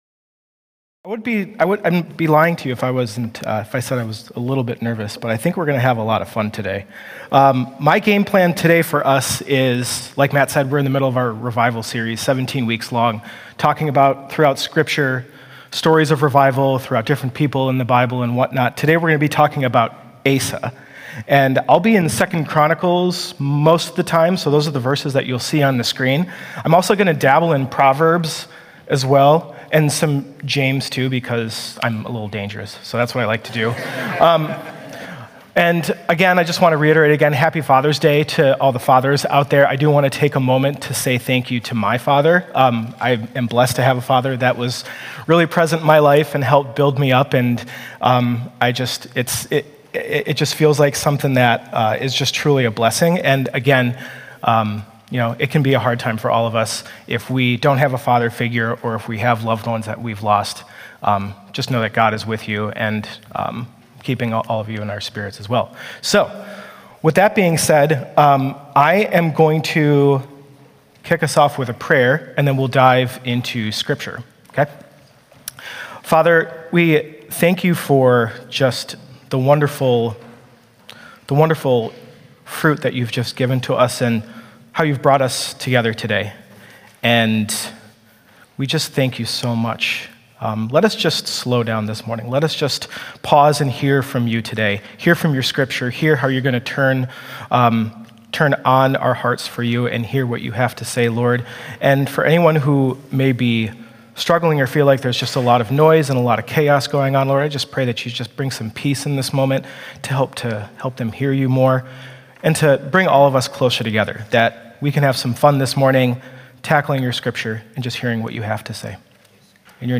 A message from the series "Revival Times."